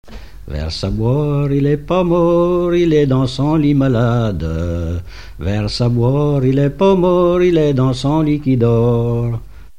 circonstance : bachique
Pièce musicale inédite